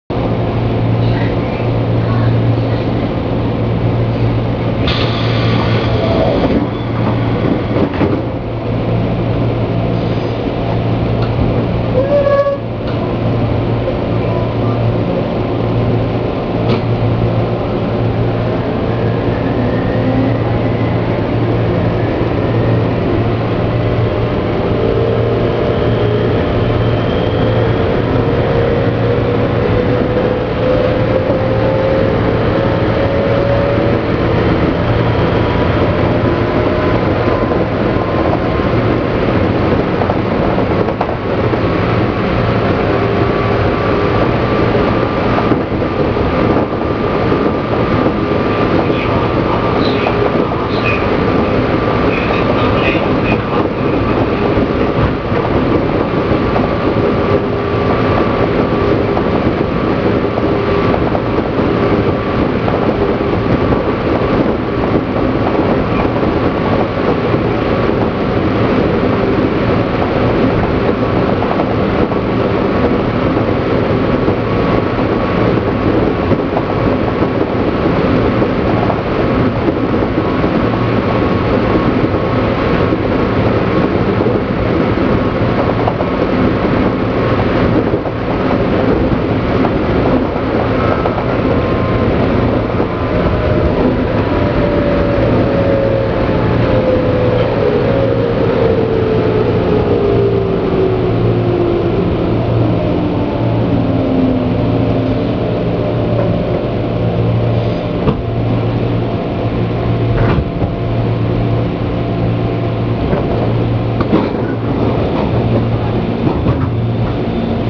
・203系走行音
【常磐線】新松戸〜馬橋（1分58秒：640KB）
車体が時々ミシミシ音を立てているのはやはり劣化が進んでいる証拠なのでしょうか。基本的に、音は201系と全く同じですが、走行中はアルミ車体故か、軽いドアが揺れに揺れてしまっています。